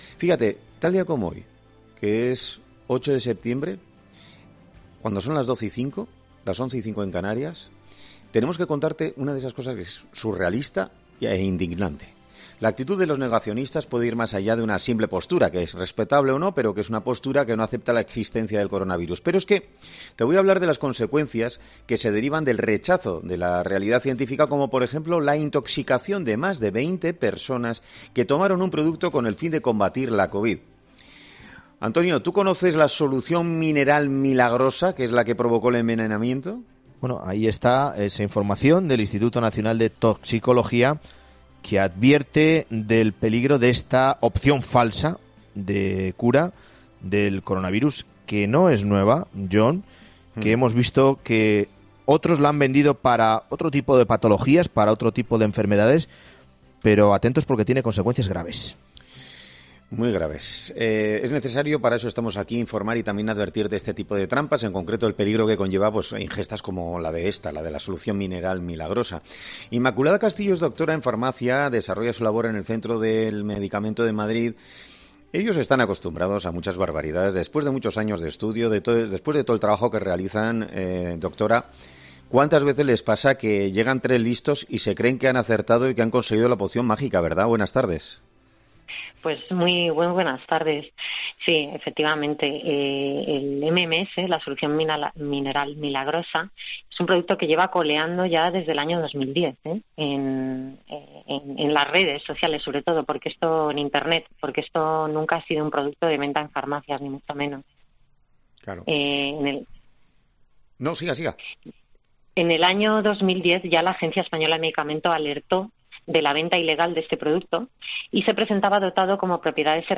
Este martes en 'Herrera en COPE' ha sido entrevistada la doctora en Farmacia